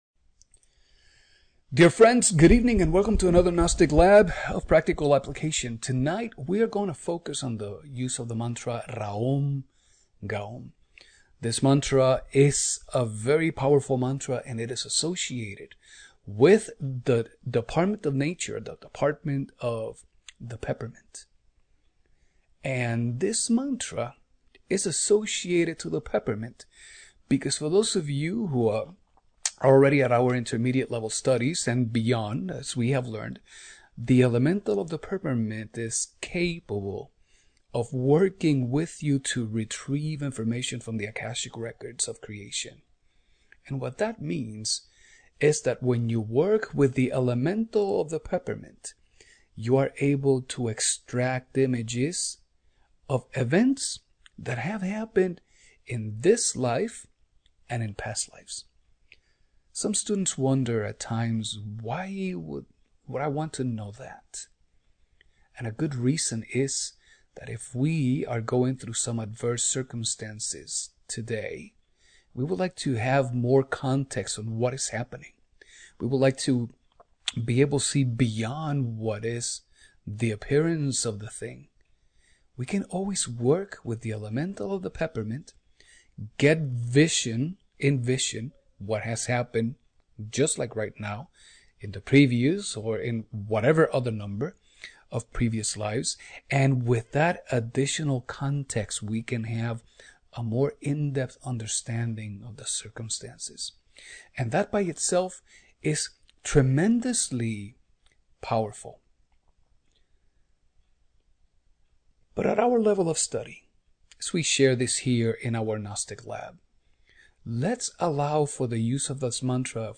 The Mantra Raom-Gaom
raom_gaom.mp3